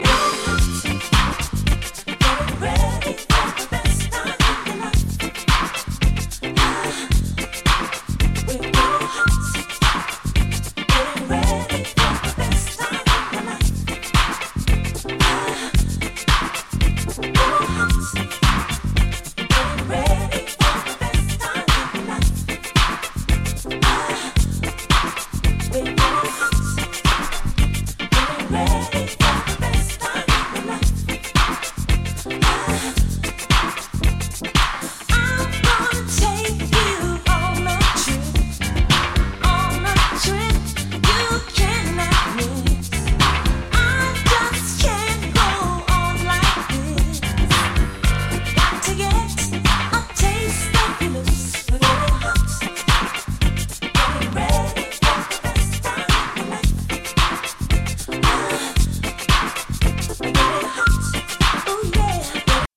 SOUL & FUNK & JAZZ & etc / REGGAE & DUB